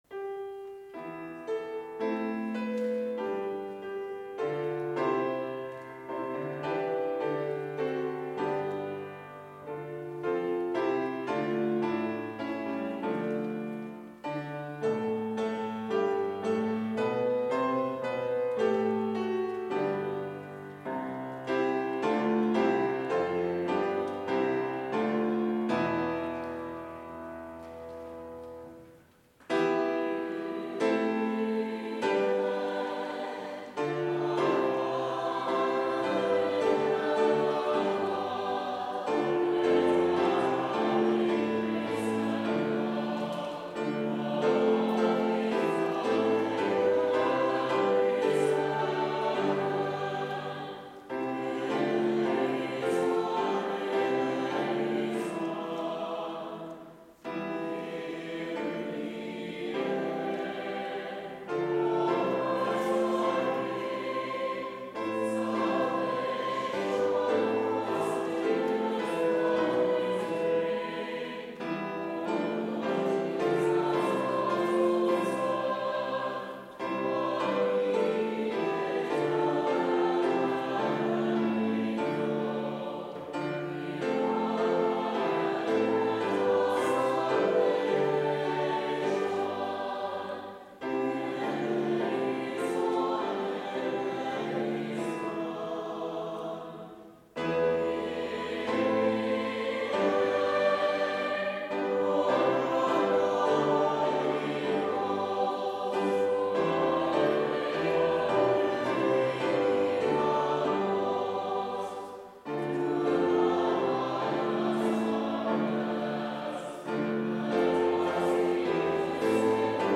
Special worship service in BLC's Trinity Chapel
Complete service audio for Reformation Organ Vespers - November 1, 2023